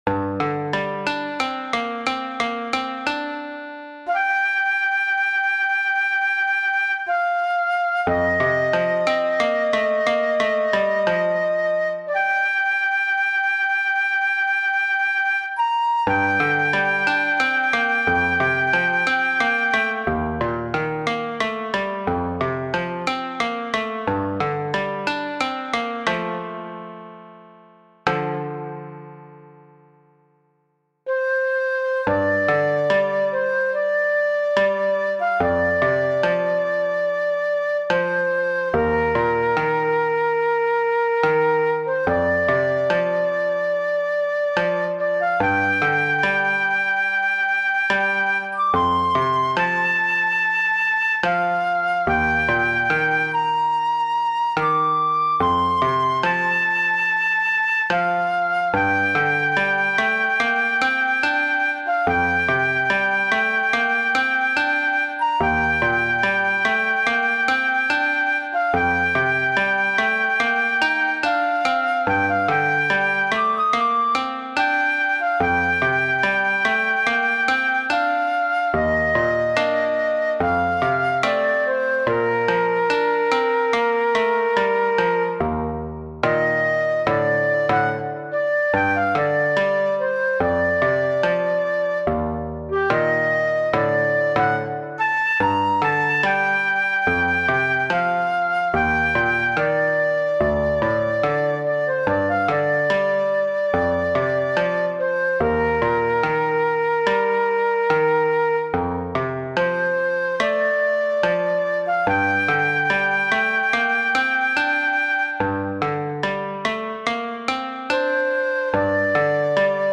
【編成】十七絃・尺八（一尺八寸） 深夜に外を出歩くと、街は昼間と異なる姿を見せてくれます。
しかしあまり行きすぎると、元の場所に帰れなくなるかも…？ホラーテイストな雰囲気の中間部にもご注目ください。